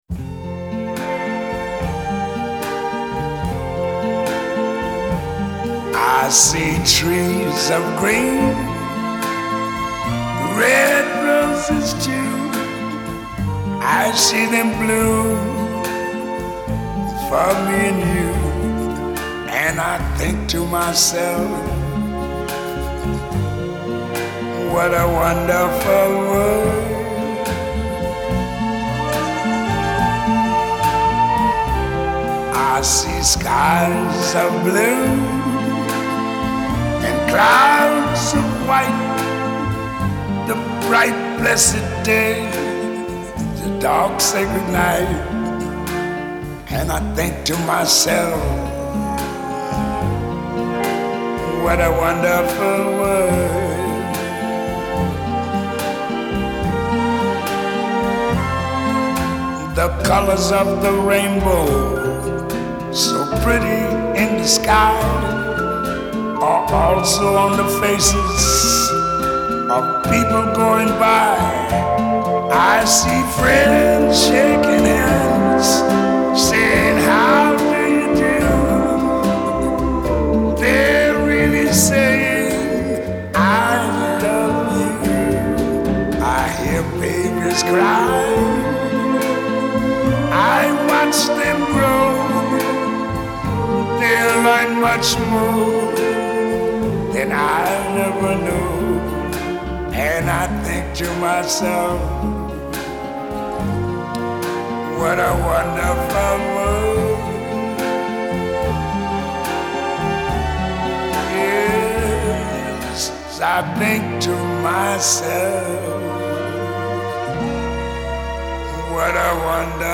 این تنظیم ارکسترال